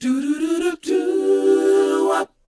DOWOP A AD.wav